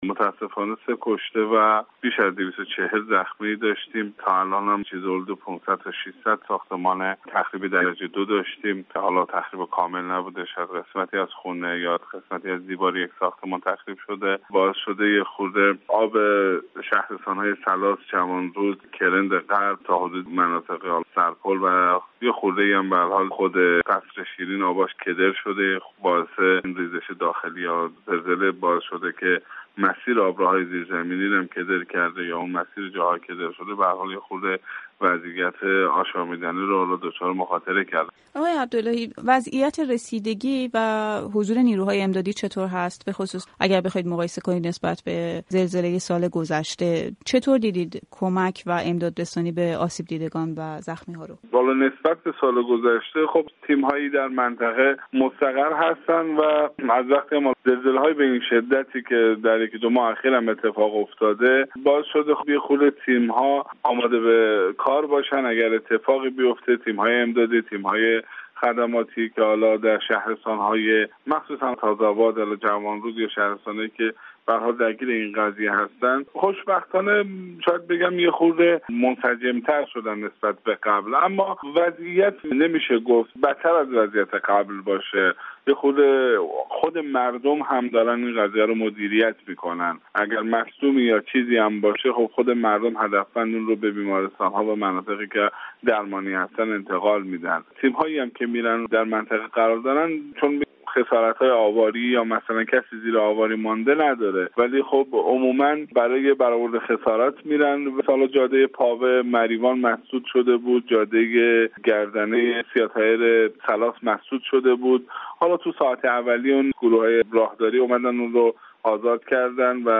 آخرین وضعیت زلزله‌زدگان استان کرمانشاه در گفت‌وگو با یک فعال مدنی